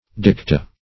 Dicta \Dic"ta\, n. pl. [L.]